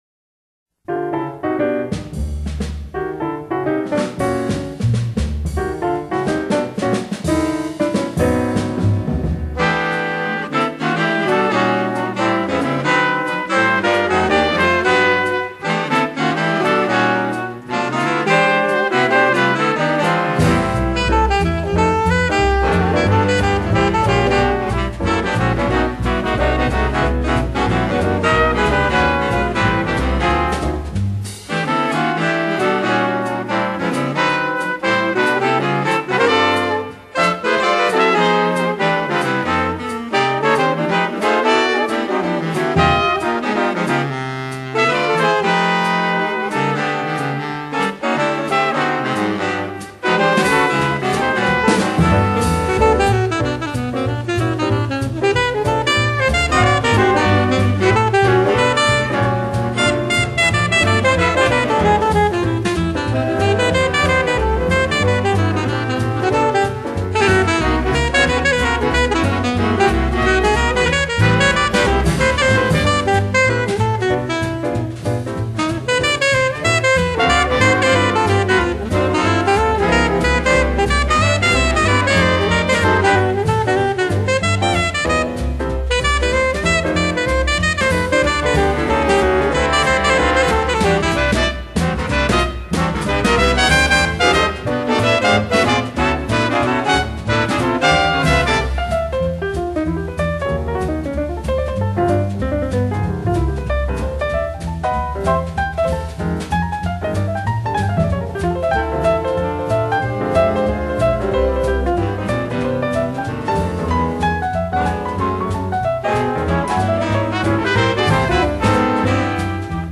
爵士萨克斯
音乐类型：Jazz